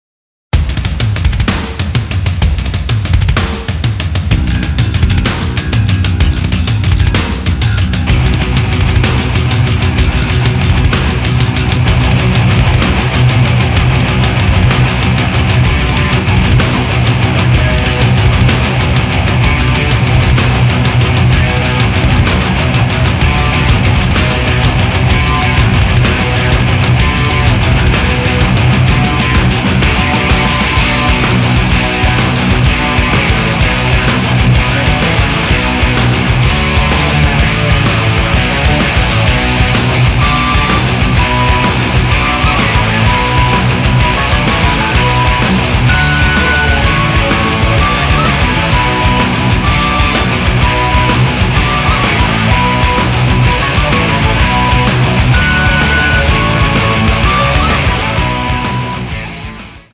фолк
Metal
Rock
Folk Metal